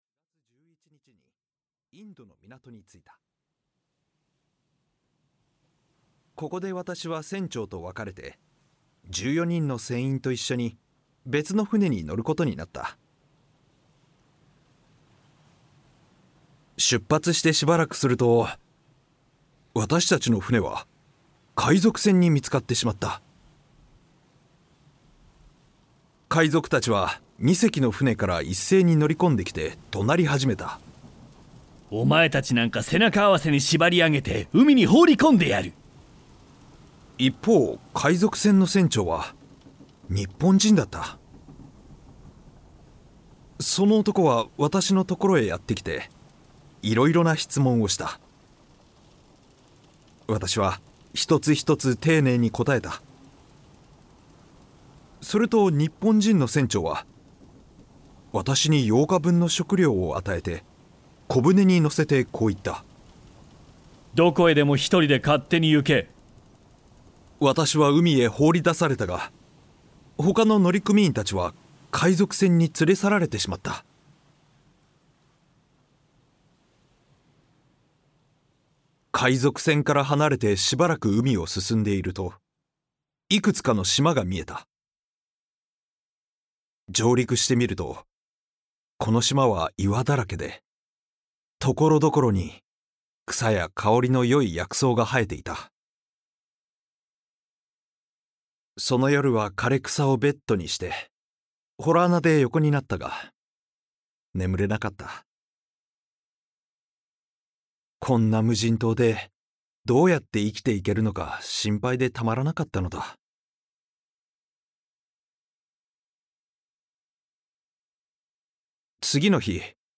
[オーディオブック] ガリバー旅行記（こどものための聴く名作 13）